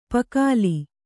♪ pakāli